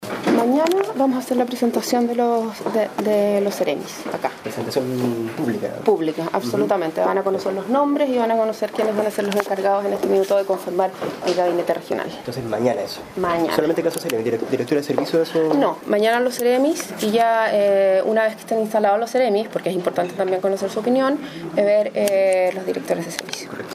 ESCUCHE AQUÍ EL ANUNCIO DE LA INTENDENTA